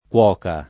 k0SSi]; part. pass. cotto [k0tto] (ma cociuto [ko©2to] nel sign. fig. di «rincresciuto») — in tutta la coniug. kU0© (pop. k) se tonico, ko© o kUo© se atono — abbastanza com., tuttavia, anche nell’uso lett., cocere ecc. senza dittongo nei sign. fig. di «scottare; rincrescere»: Coce il rosso nel costato [ke il r1SSo nel koSt#to] (D’Annunzio) — per cuocio, cuocia, ecc., antiq. cuoco [kU0ko], cuoca [